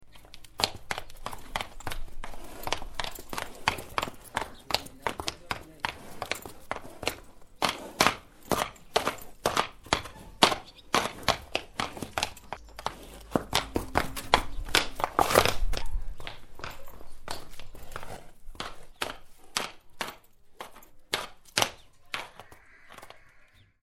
Horse Walking Sound Effect
Animal Sounds / Horse Sounds / Sound Effects
Horse-foot-steps-sound-effect.mp3